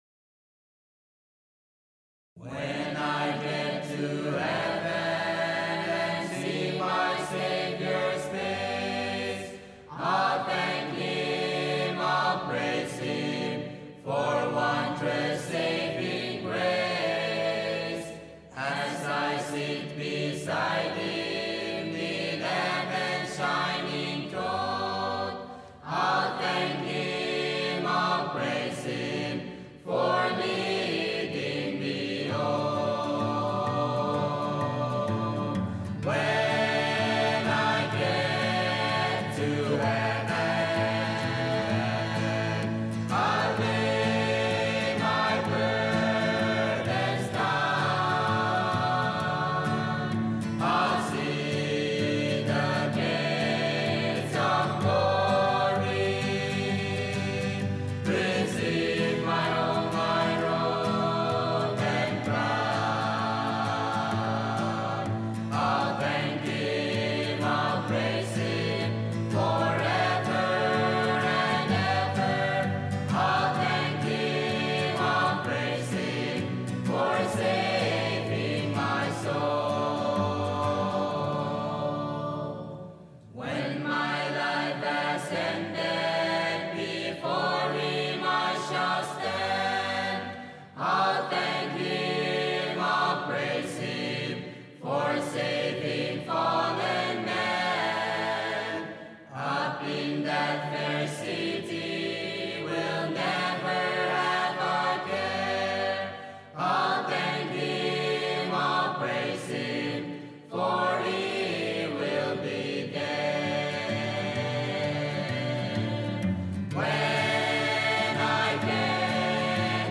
Thanksgiving drama